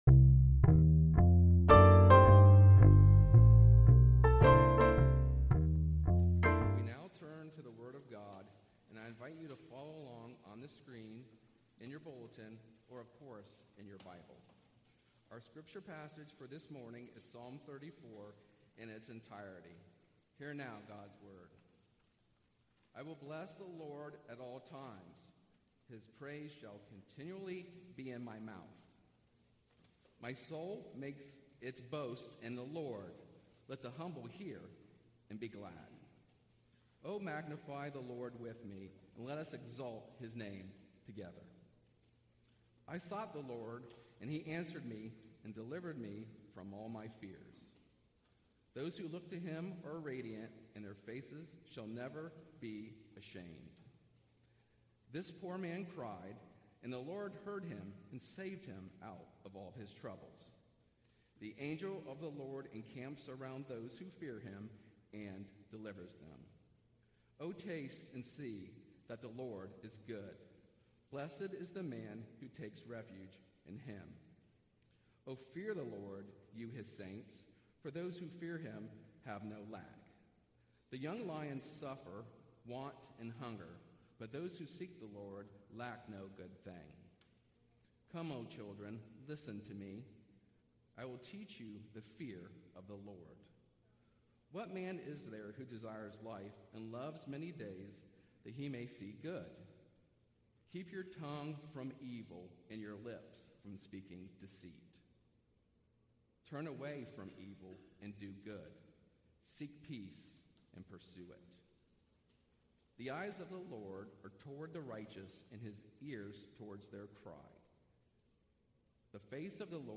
Passage: Psalm 34 Service Type: Sunday Worship